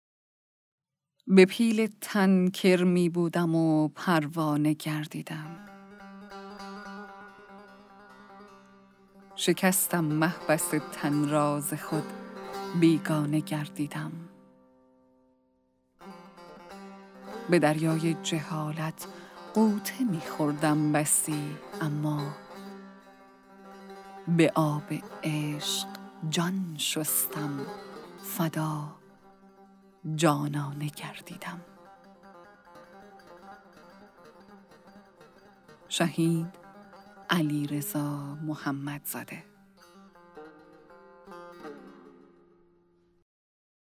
«شمال حماسه» کتاب صوتی اشعار شاعران شهید استان گیلان